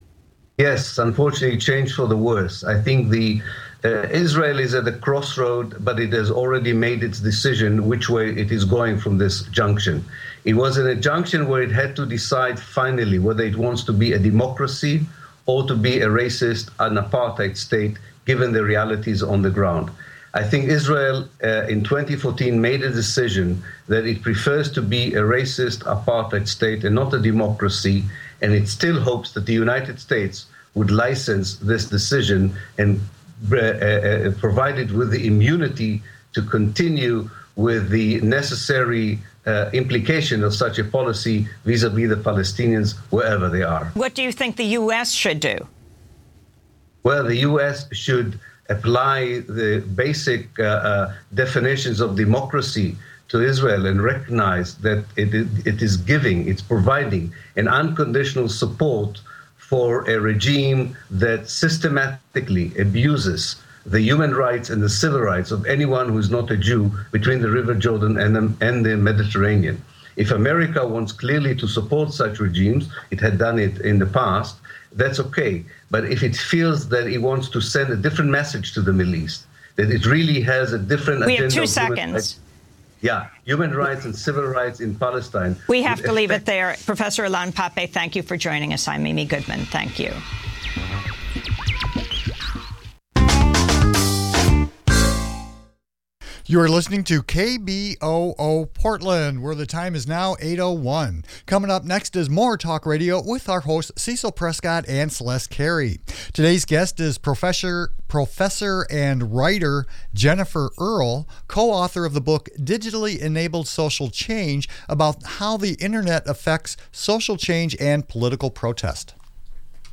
More Talk Radio